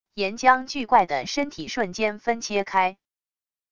岩浆巨怪的身体瞬间分切开wav音频